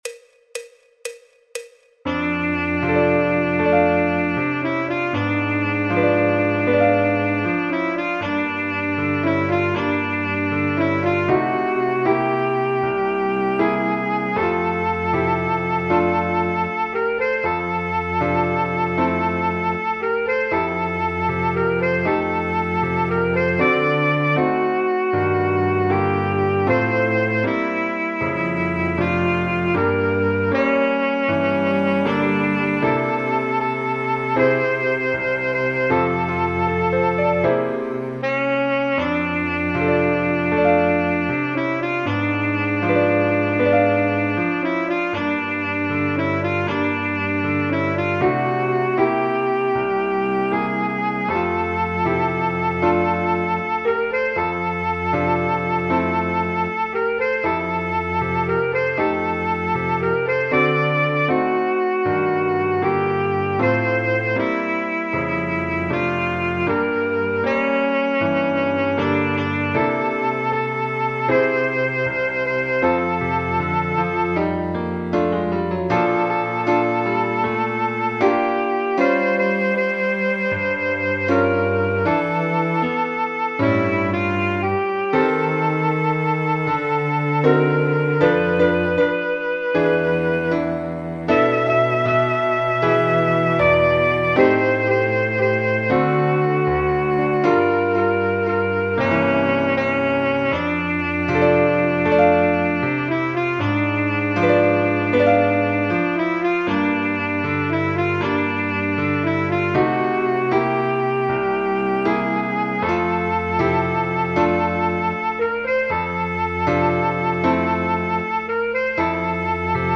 El MIDI tiene la base instrumental de acompañamiento.
Saxofón Alto / Saxo Barítono
Re Mayor
Jazz, Popular/Tradicional